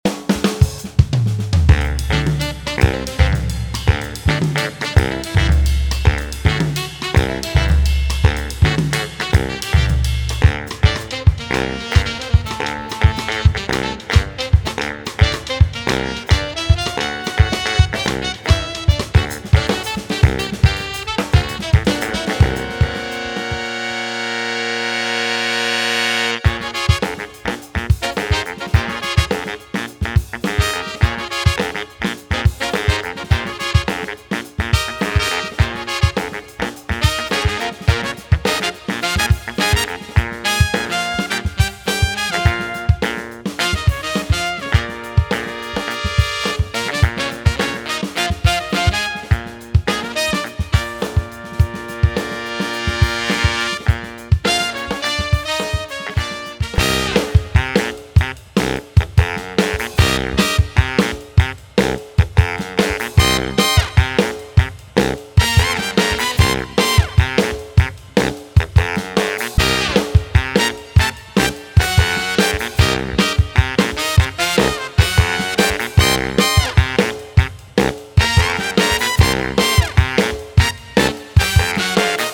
57 Ensemble Horn Loops: These loops bring the full power of a horn section to your music, creating lush, layered textures that command attention and drive your compositions forward.
29 Alto Sax Loops: Smooth and sultry, these alto sax loops add a touch of sophistication and warmth, ideal for creating rich harmonic layers or standout melodies.
14 Baritone Sax Loops: Deep and resonant, the baritone sax loops provide a solid, grounding presence, adding weight and gravitas to your arrangements.
22 Trumpet Loops: Bright and bold, these trumpet loops cut through the mix with clarity and precision, delivering impactful stabs and melodic accents.
Each loop is recorded with pristine clarity and performed by professional musicians, ensuring that every note delivers the vibrant, authentic character of live horn performances.